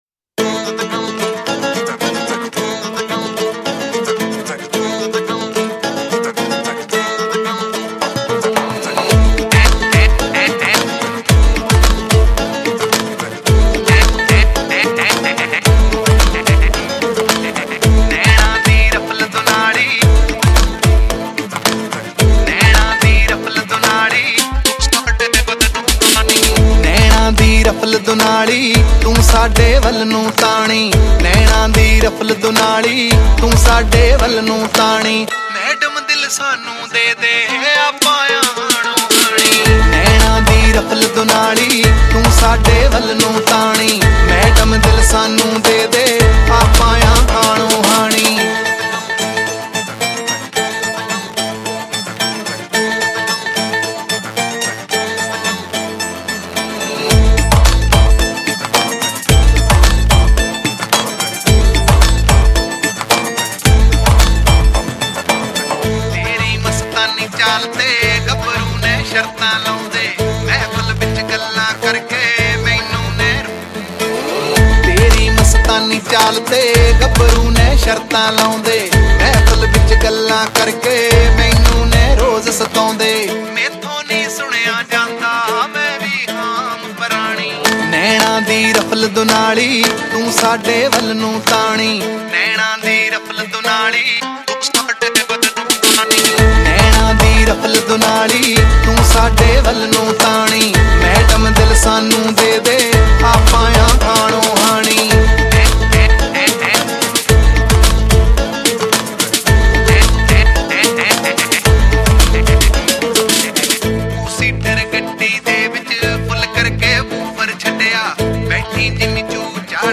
Category: Punjabi Single